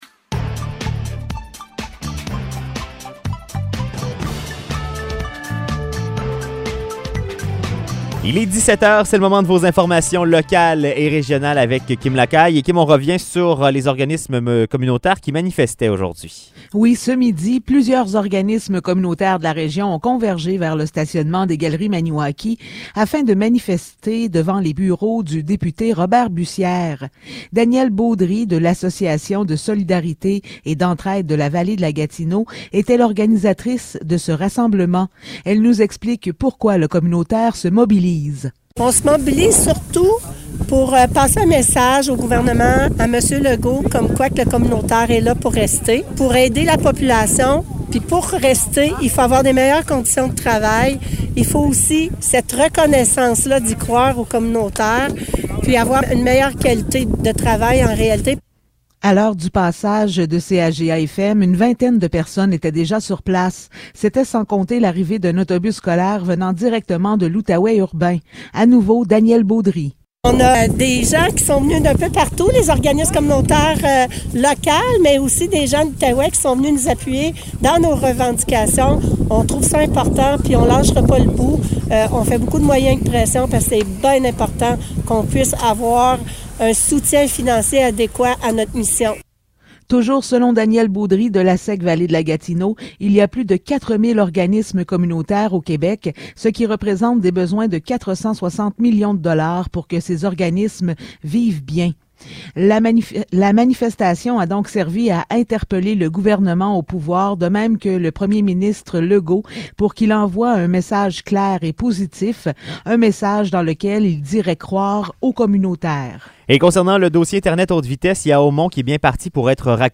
Nouvelles locales - 23 février 2022 - 17 h